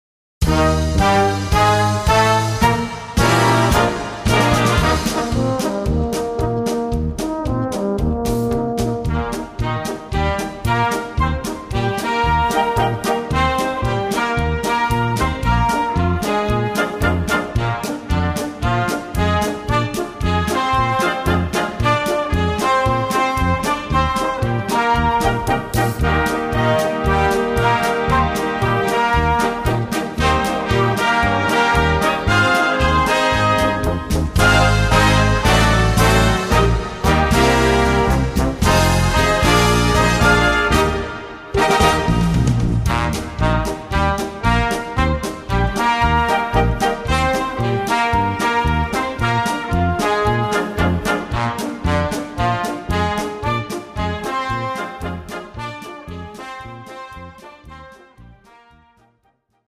Style: Party, Stimmungsmusik Reset all filters
Brass Band